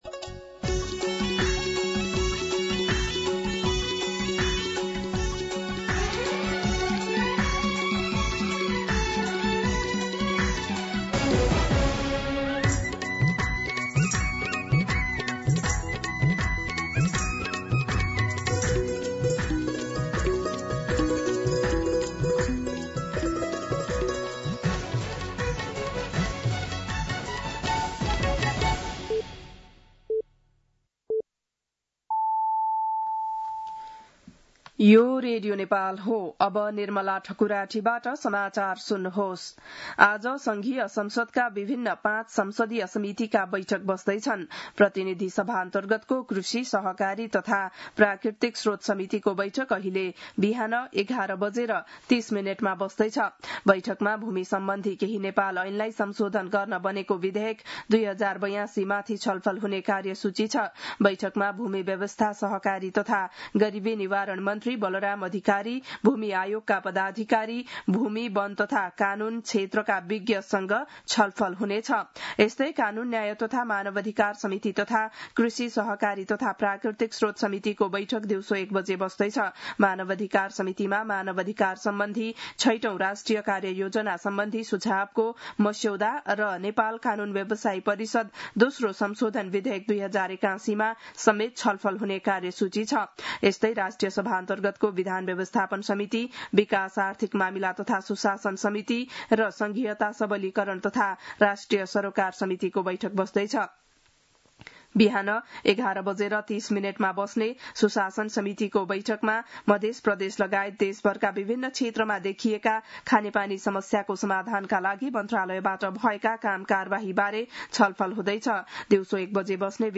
बिहान ११ बजेको नेपाली समाचार : १५ साउन , २०८२